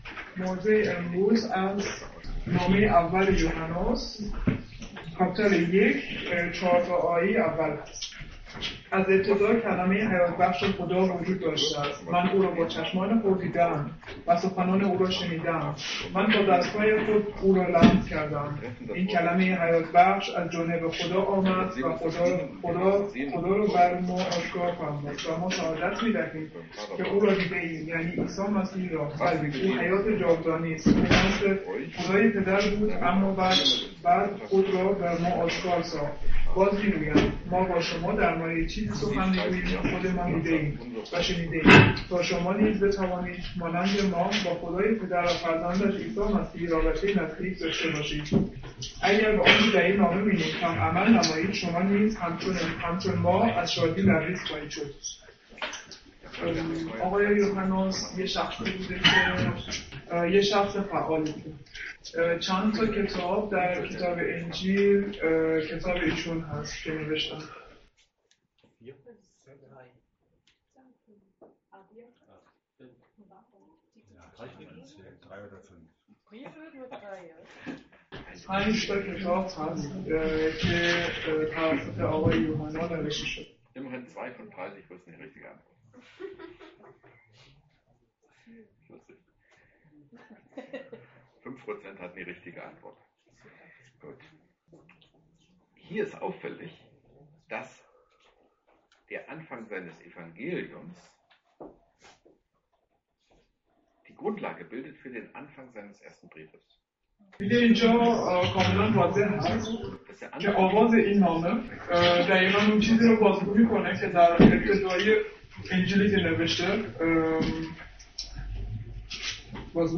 Johannes 1,1-4 | Übersetzung in Farsi